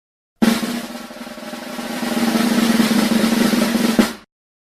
sfx_drumroll.mp3